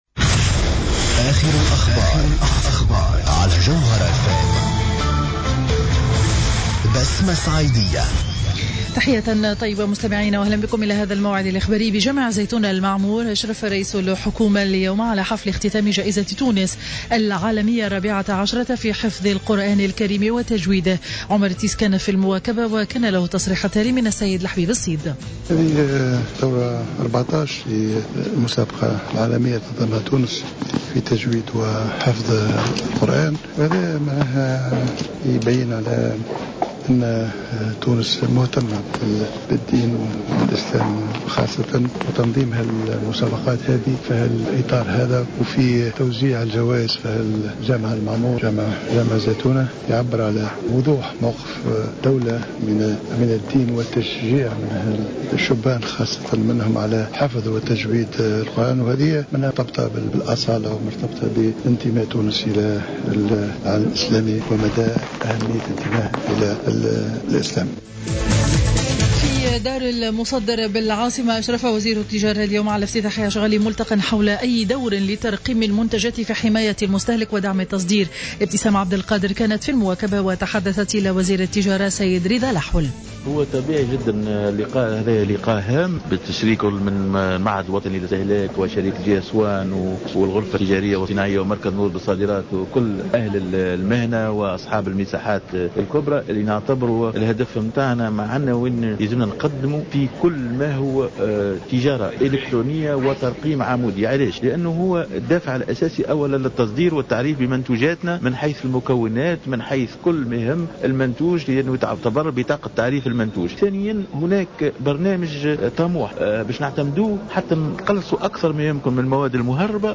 نشرة أخبار منتصف النهار ليوم الخميس 07 ماي 2015